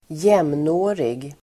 Uttal: [²j'em:nå:rig]